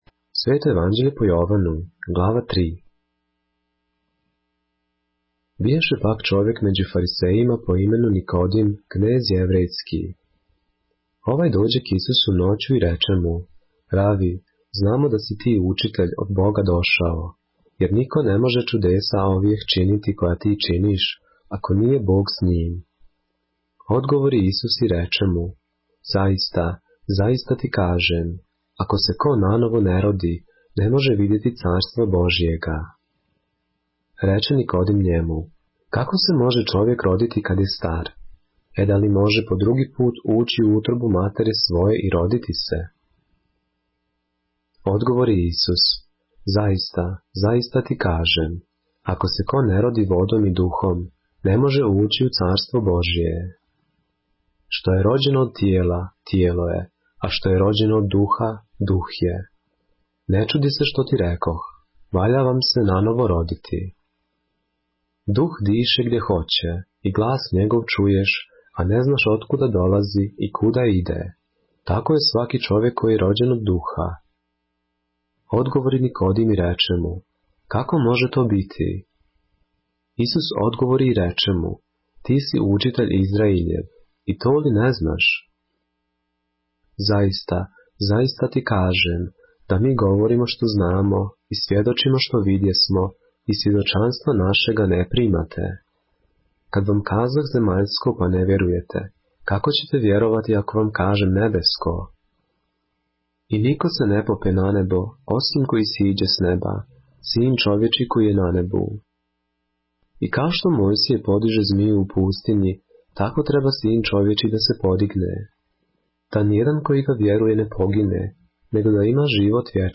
поглавље српске Библије - са аудио нарације - Христос Спаситељ - Jovanu - ЈОВАН 3